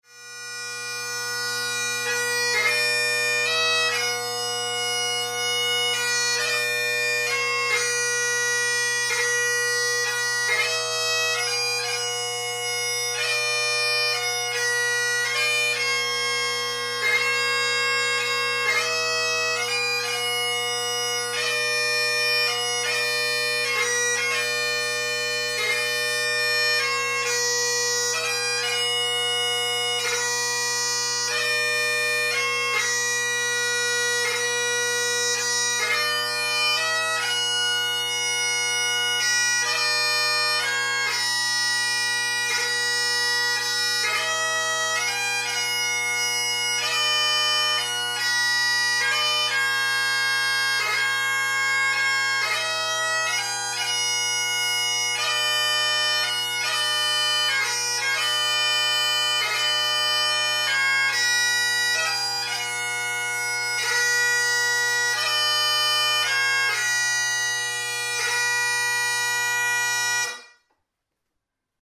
on the RJM bagpipe with RJM African blackwood solo chanter
Hymn/ Air